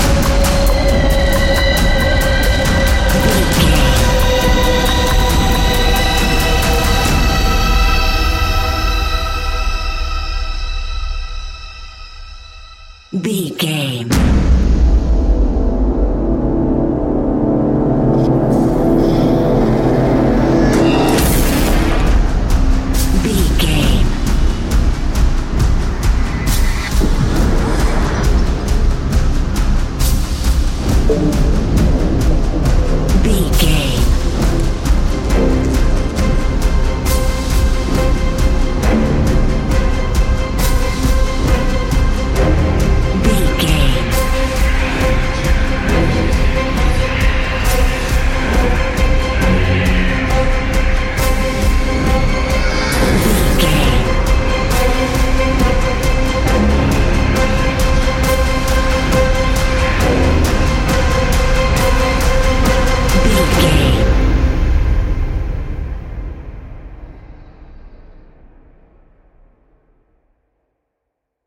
Fast paced
In-crescendo
Ionian/Major
C♯
industrial
dark ambient
EBM
experimental
synths